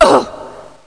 femouch2.mp3